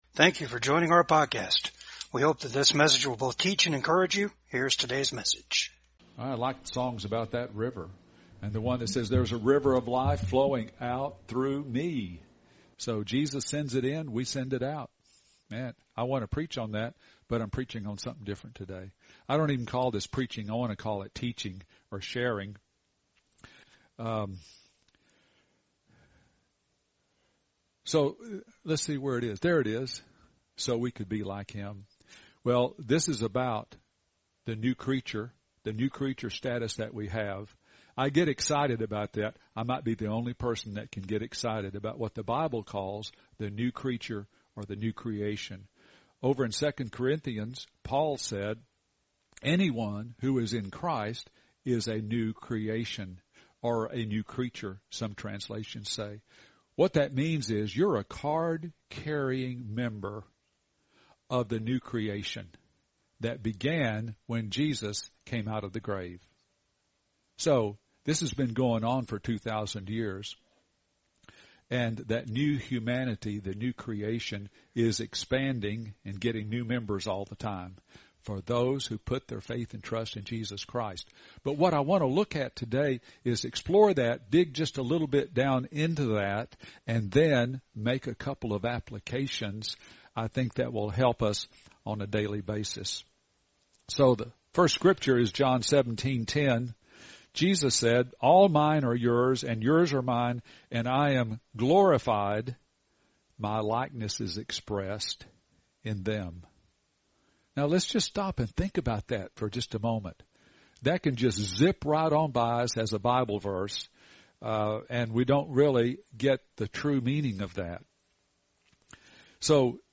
John 17:10 Service Type: VCAG WEDNESDAY SERVICE YOU WERE BORN IN THE IMAGE OF ADAM.